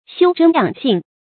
修真養性 注音： ㄒㄧㄨ ㄓㄣ ㄧㄤˇ ㄒㄧㄥˋ 讀音讀法： 意思解釋： 學道修行，涵養性情。